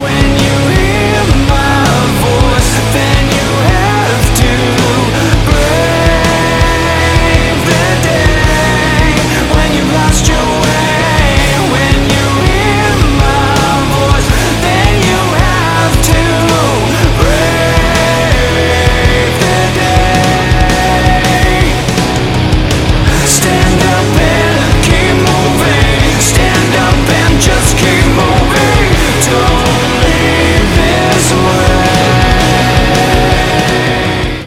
• Качество: 192, Stereo
Спокойный мелодичный рок